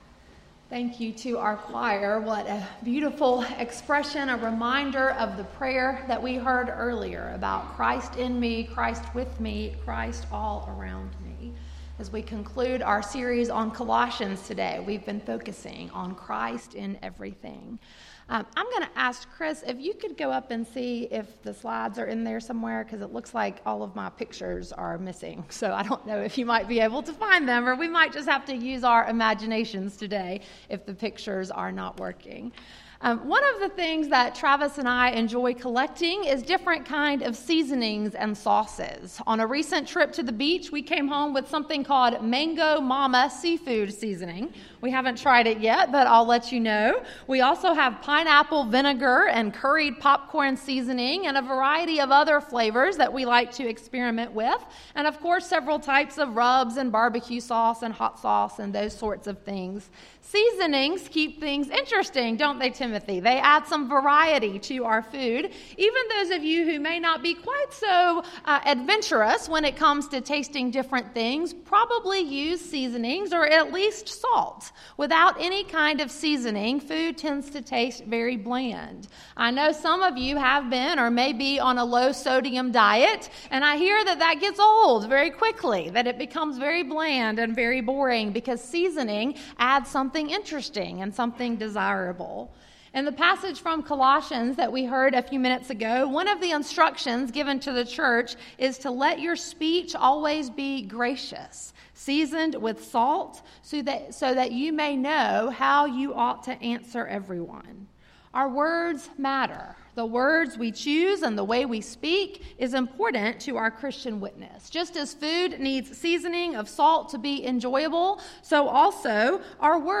Sermon, Worship Guide, and Announcements for August 25, 2019 - First Baptist Church of Pendleton